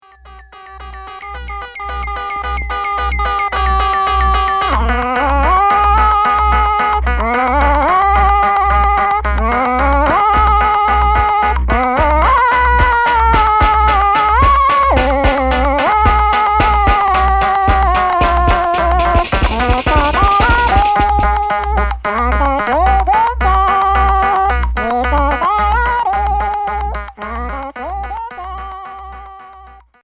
Cold expérimental